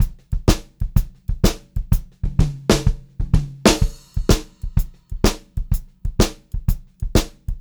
126CLBEAT2-L.wav